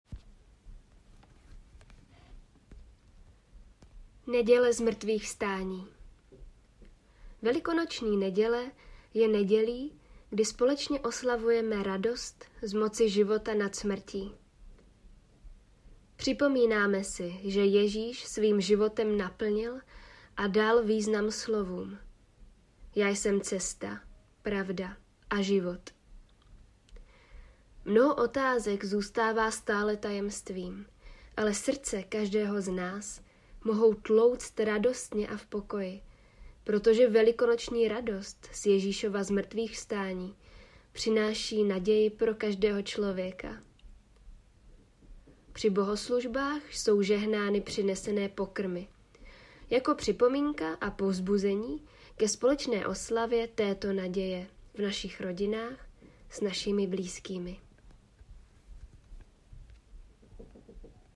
Lektorské úvody k triduu